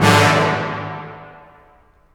HN_DIRTYHORN.WAV